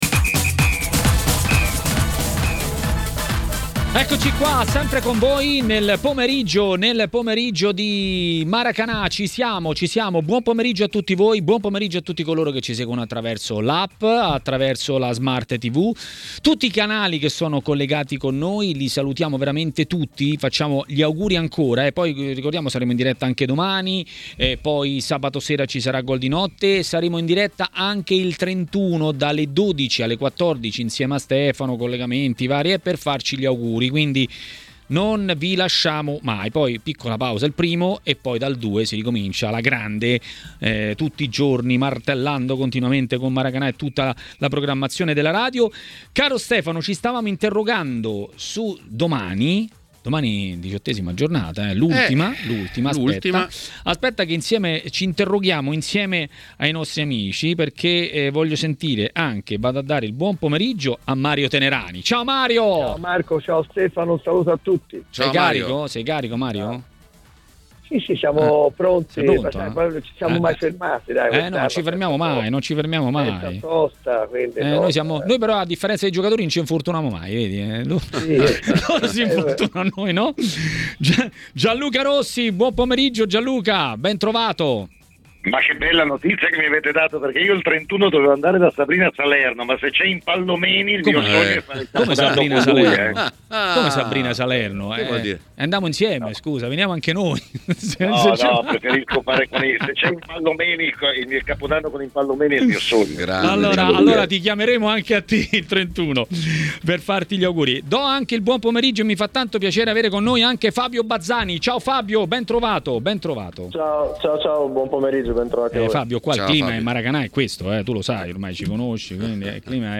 A parlare dei temi del giorno a Maracanà, nel pomeriggio di TMW Radio, è stato l'ex calciatore Fabio Bazzani.